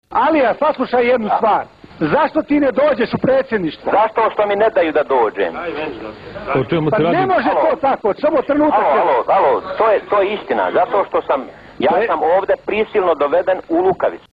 Razgovor Izetbegovića i Ganića